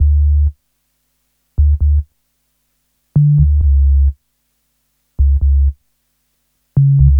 HP133BASS1-L.wav